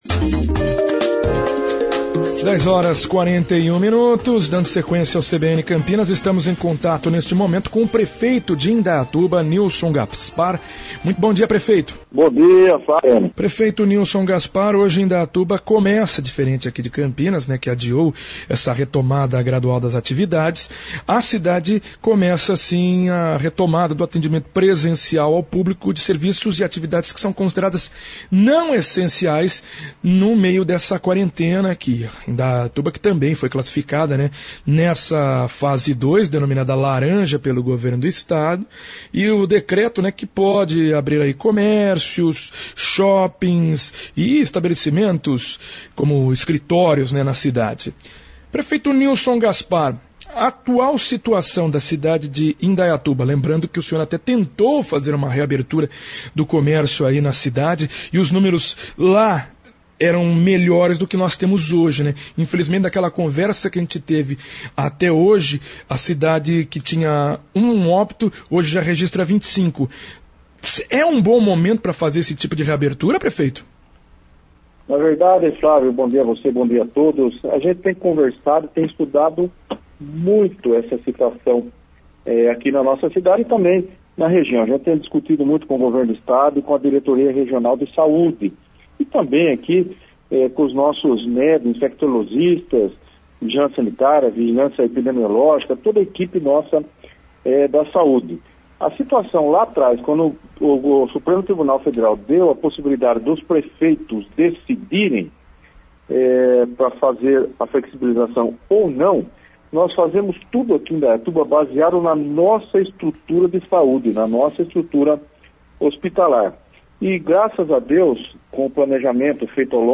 Prefeito de Indaiatuba explica reabertura do comércio - CBN Campinas 99,1 FM